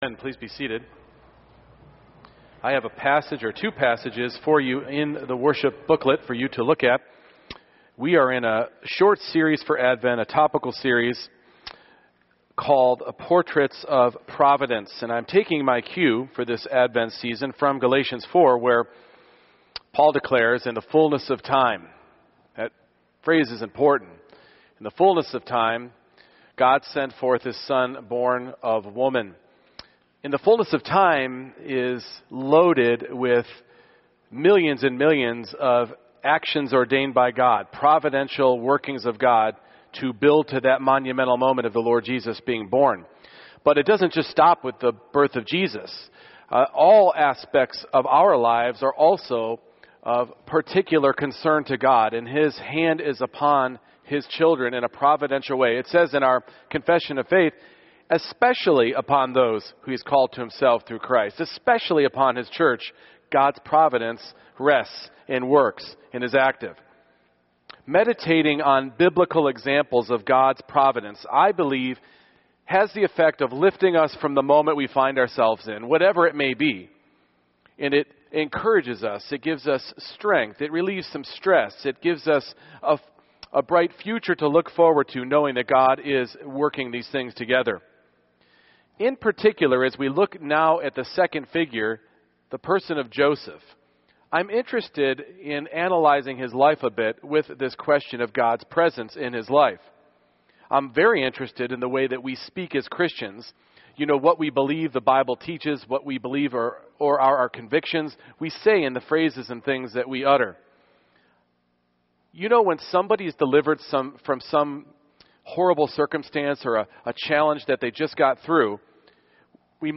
Genesis 37:18-28 Service Type: Morning Worship When was God most present in the life of Joseph?